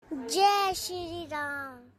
Bhakti Ringtones Child Voice Ringtones
Notification Ringtones